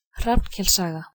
Hrafnkels saga (Old Norse pronunciation: [ˈhrɑvnˌkels ˌsɑɣɑ]; Icelandic pronunciation: [ˈr̥apn̥ˌcɛls ˌsaːɣa]